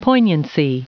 Prononciation du mot poignancy en anglais (fichier audio)
Prononciation du mot : poignancy